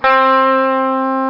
Guitar Sound Effect
Download a high-quality guitar sound effect.
guitar-1.mp3